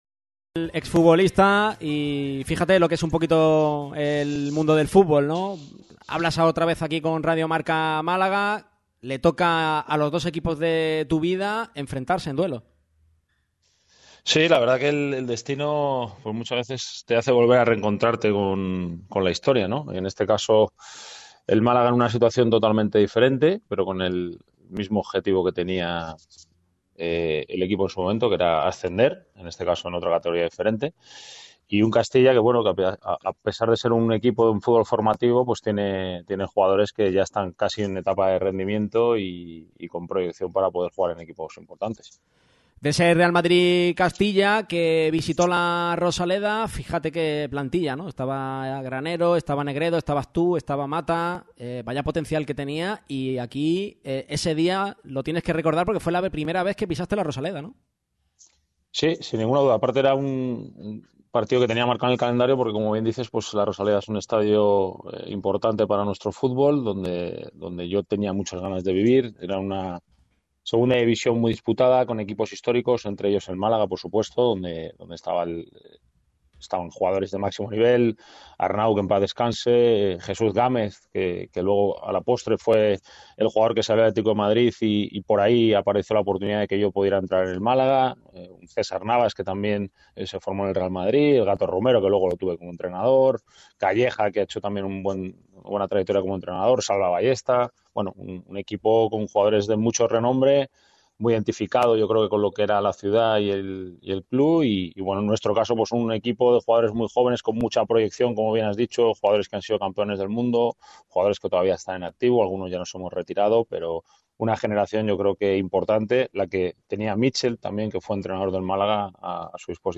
Este viernes se pasó por el micrófono rojo Miguel Torres. El ex jugador del Málaga CF y Real Madrid ha atendido la llamada de Radio MARCA Málaga en la previa del Málaga-Real Madrid Castilla.